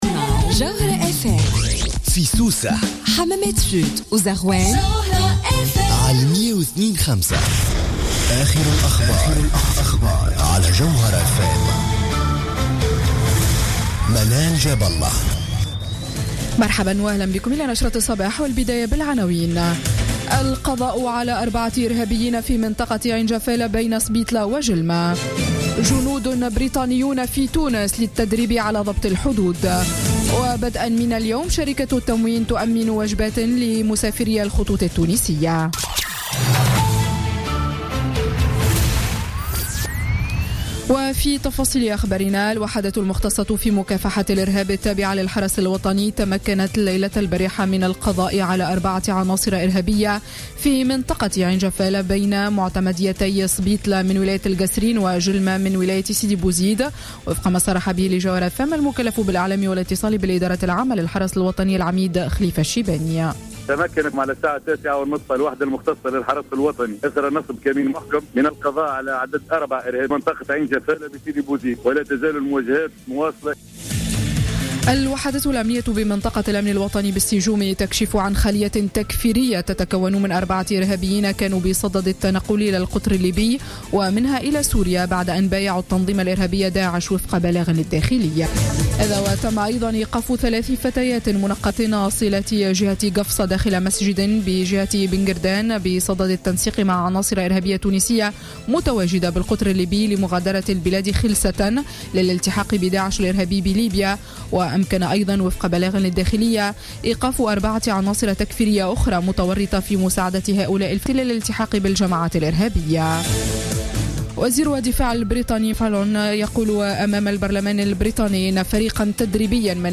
نشرة أخبار السابعة صباحا ليوم الثلاثاء 1 مارس 2016